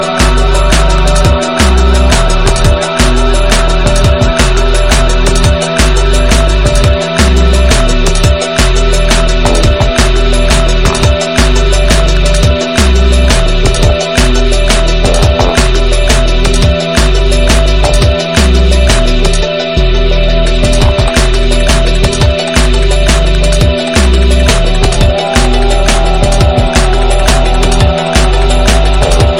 TOP >Vinyl >Drum & Bass / Jungle
TOP > Deep / Liquid